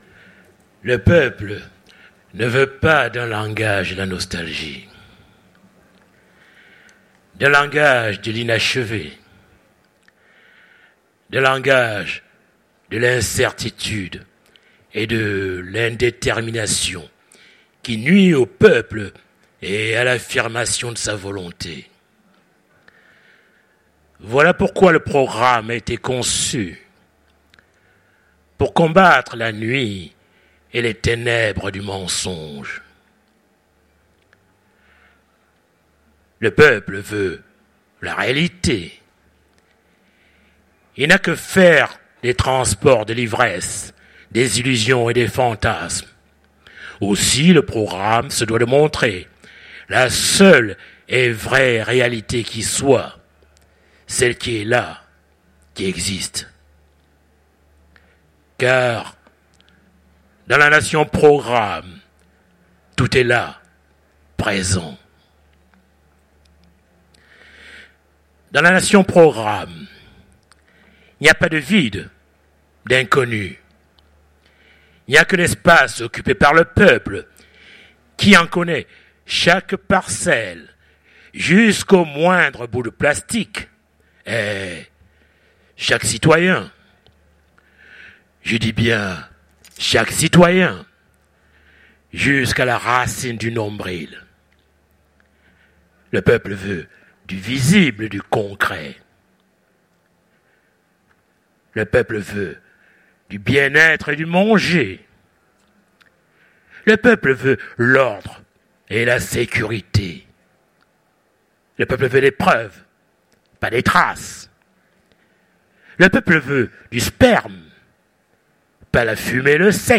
Utopiales 2015 : Conférence Le cabaret du futur